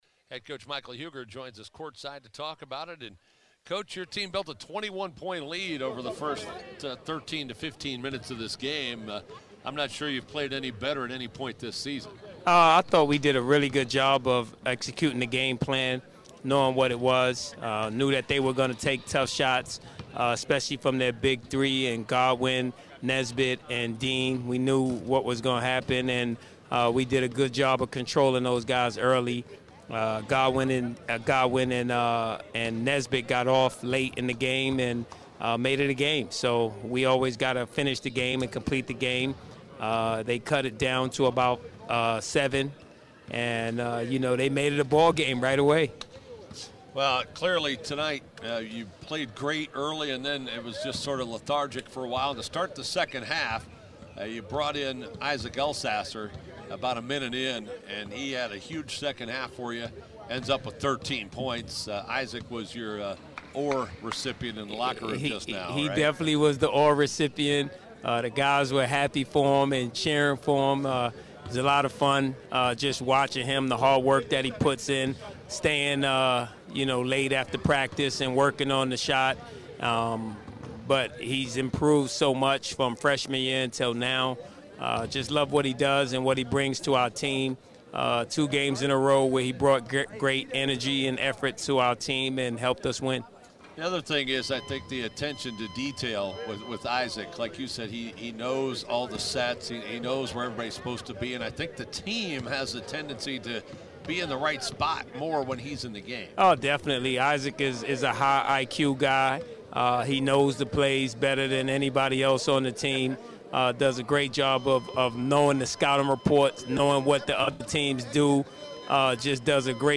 Full postgame interview
*The fire alarm at the end of the broadcast was a false alarm inside the arena.